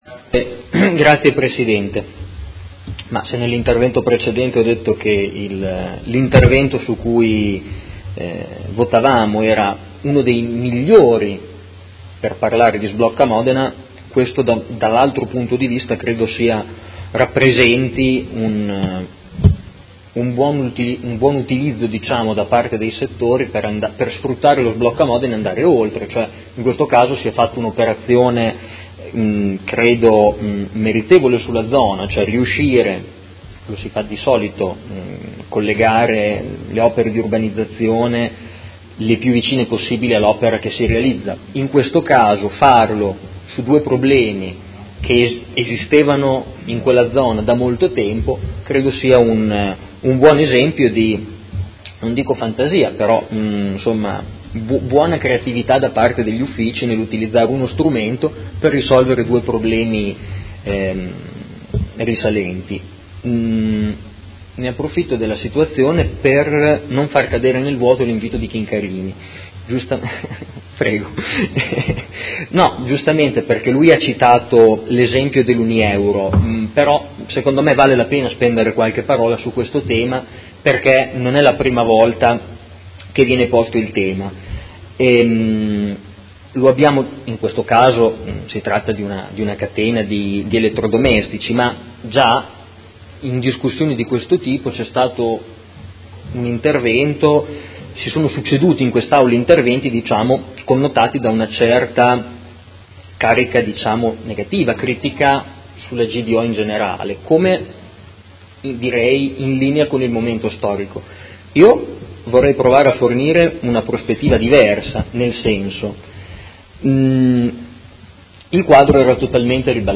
Marco Forghieri — Sito Audio Consiglio Comunale
Seduta del 13/07/2017 Dibattito.